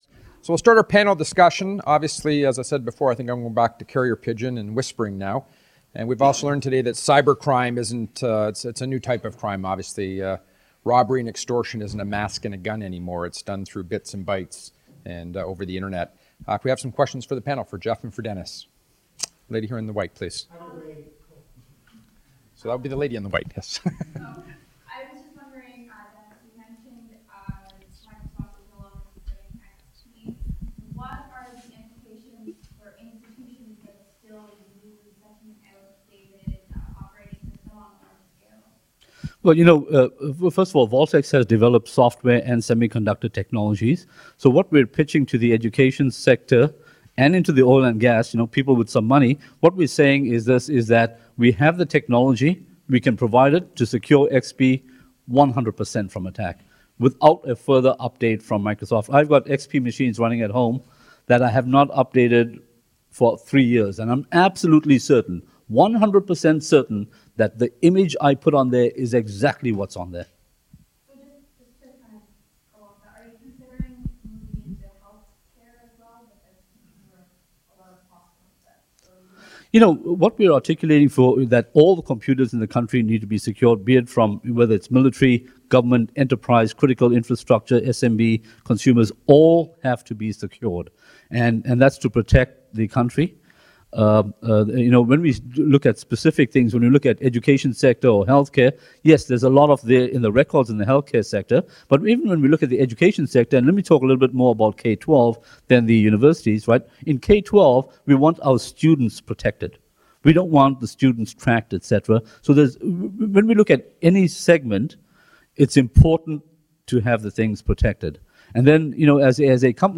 NATO-Cyber-Security-Conference-2014-Panel-II-Part-Three.mp3